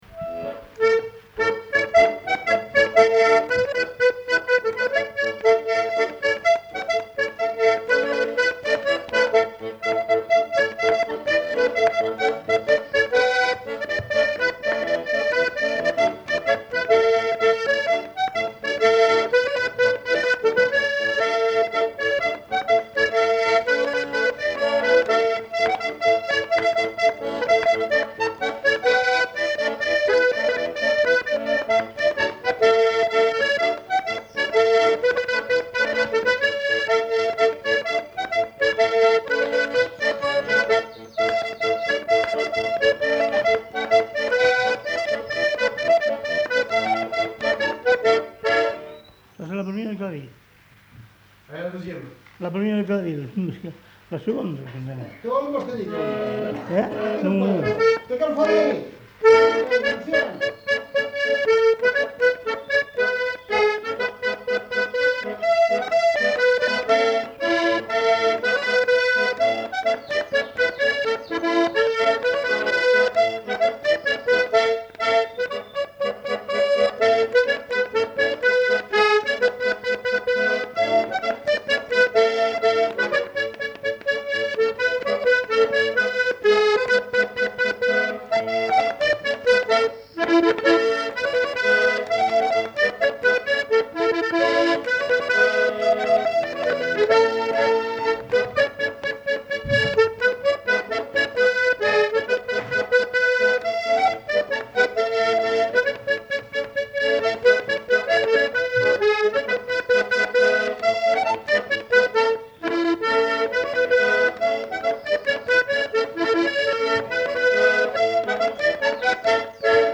Aire culturelle : Lauragais
Lieu : Gardouch
Genre : morceau instrumental
Instrument de musique : accordéon
Danse : quadrille